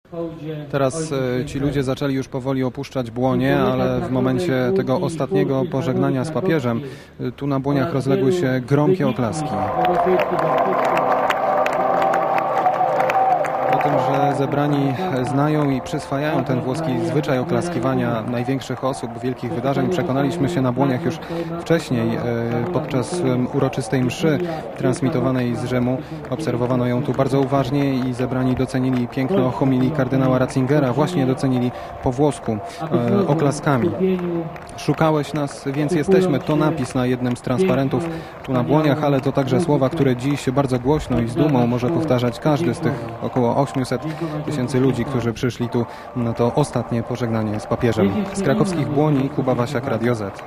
Kilkaset tysięcy osób zgromadzonych na krakowskich Błoniach oklaskami żegnało Jana Pawła II.